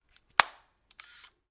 相机快门和复卷缺口压缩器
描述：一个相机快门声的混音版。这个版本有一个深槽滤波器以摆脱快门后恼人的乒乒乓乓声，还有一些压缩以使它听起来更硬。
标签： 环境 声音的研究 单击 压缩 摄像机 缺口 快退 快门
声道立体声